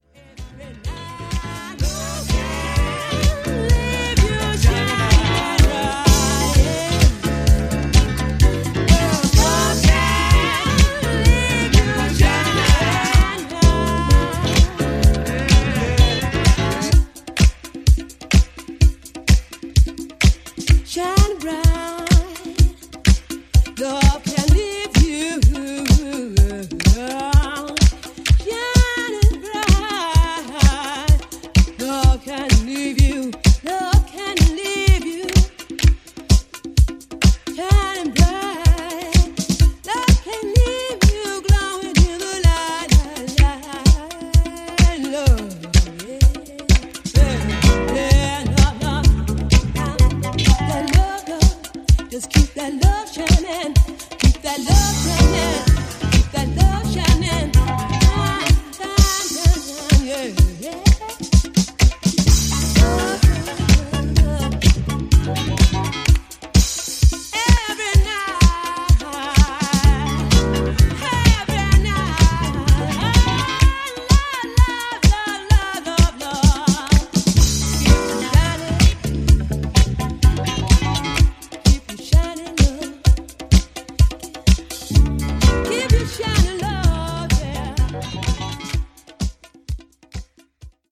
percussive disco
is a cool leftfield rocky jam
Disco edits